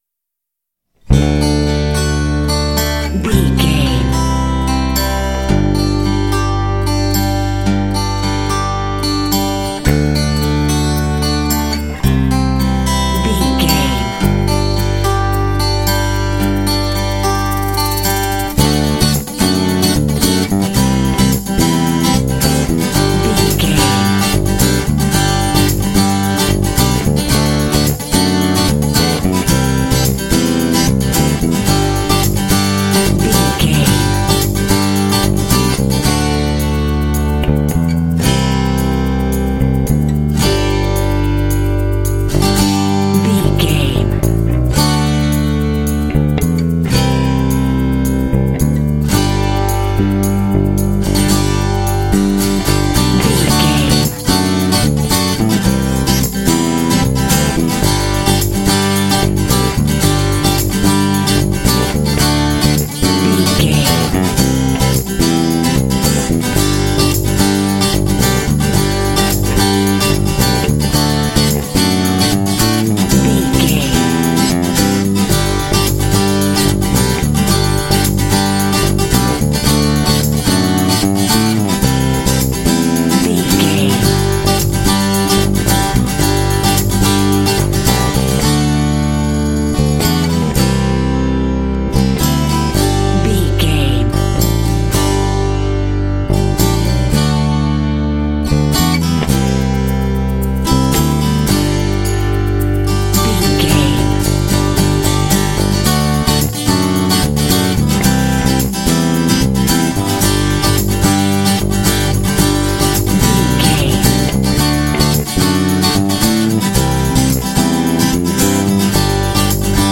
Uplifting
Ionian/Major
optimistic
happy
bright
acoustic guitar
bass guitar
percussion
pop
rock
indie